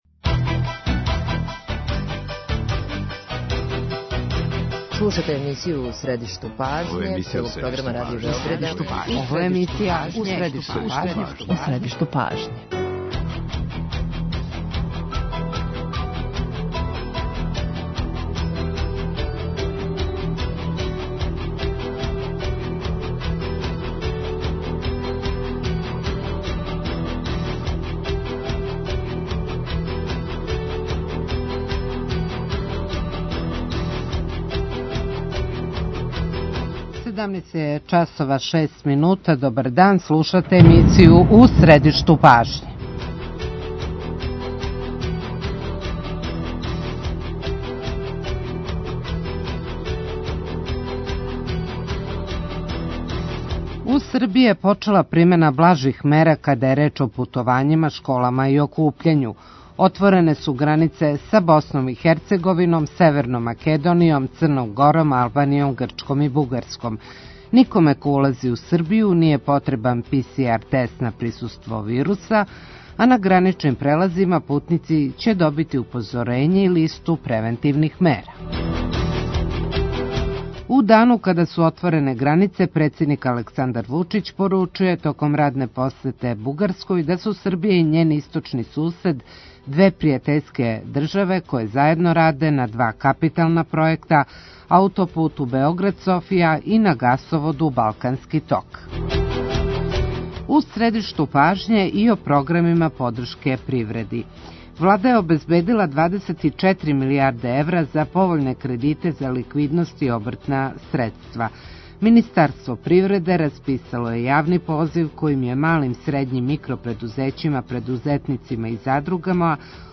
Саговорница емисије је Катарина Обрадовић Јовановић, помоћник министра привреде.